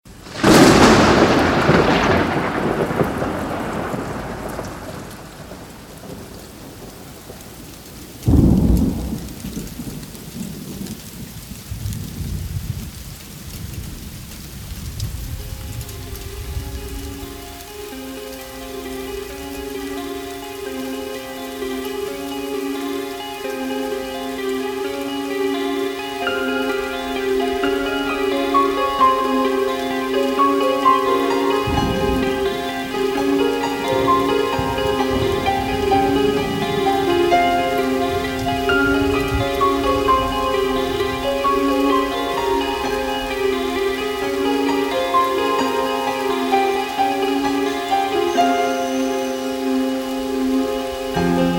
Звуки грома, грозы
Гром молнии и шум летнего дождя